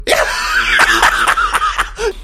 Joker Laugh 1
crazy evil heath insane joker laugh laughing laughter sound effect free sound royalty free Funny